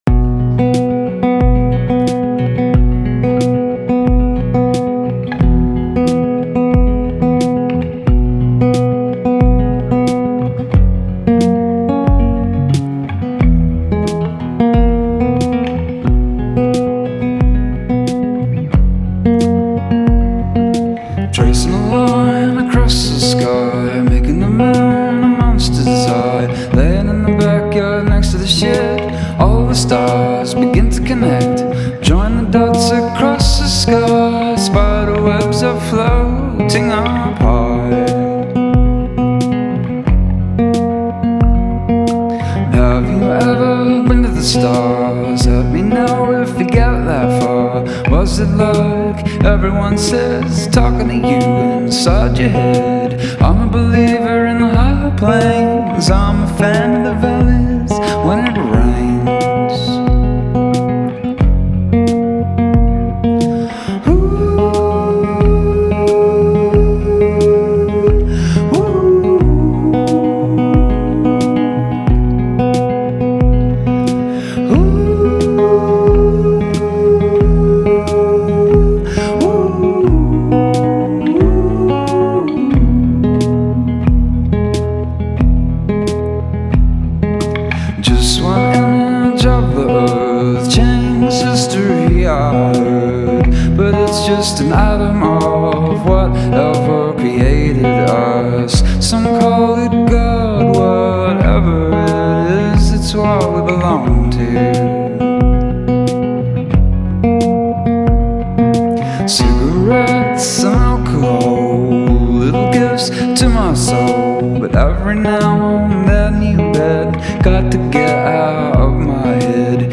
Home > Music > Singer-Songwriter > Psych-Folk